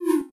Hit5.wav